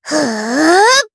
Miruru-Vox_Casting2_jp.wav